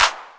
808-Clap10.wav